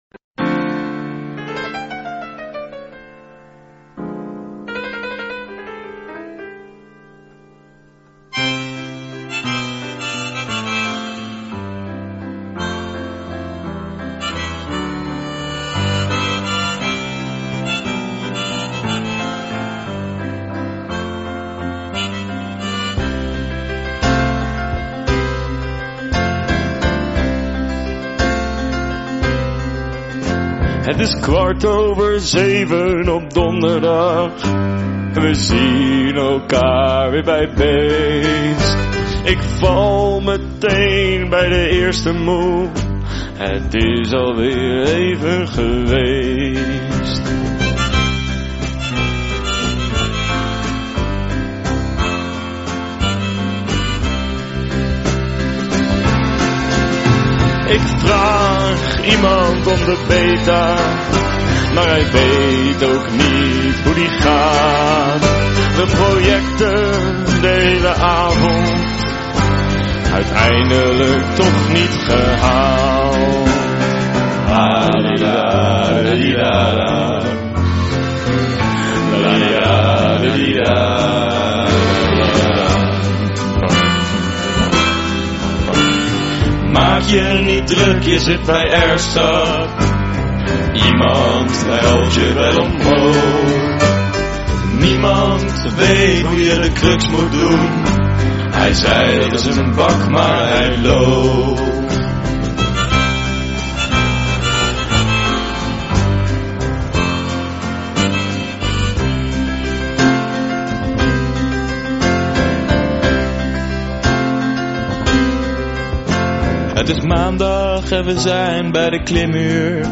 Vocal gymnmasts (van links naar rechts):